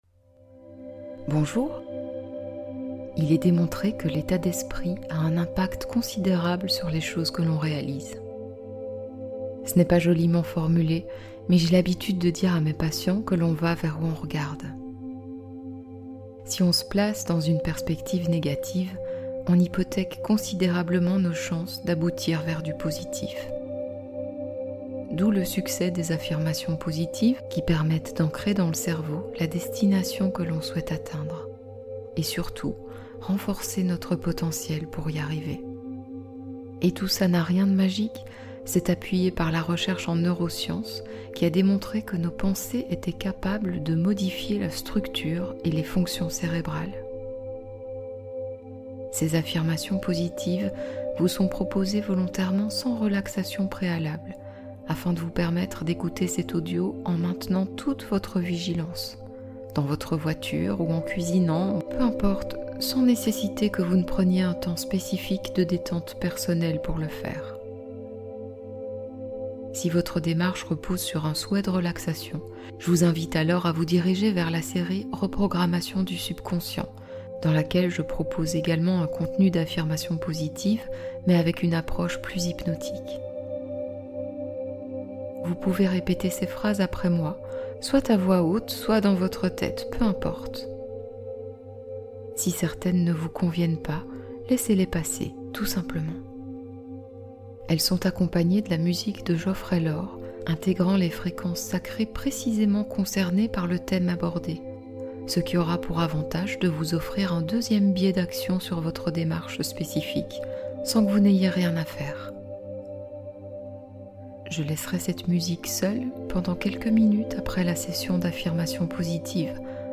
Guérissez votre dépendance affective | Affirmations + Fréquence Solfeggio 528 Hz relations saines